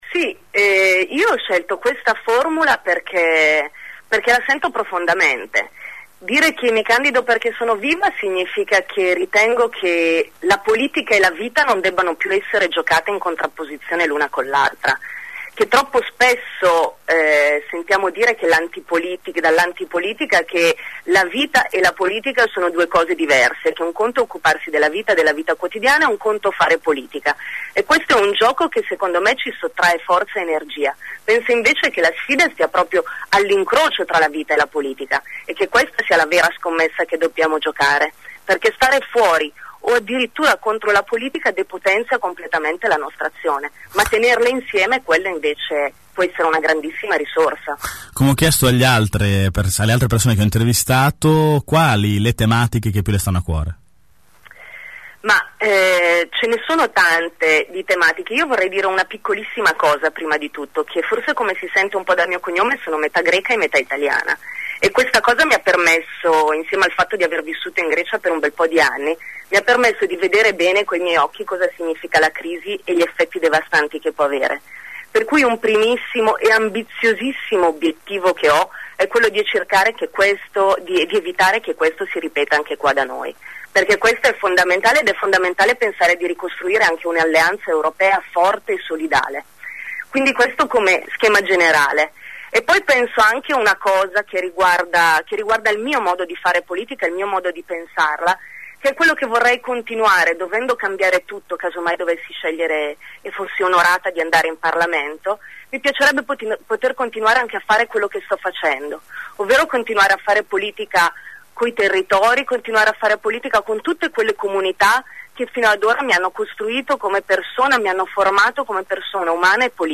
Venerdì mattina durante AngoloB abbiamo organizzato una tavola rotonda con alcuni dei candidati.
Per Sel abbiamo intervistato: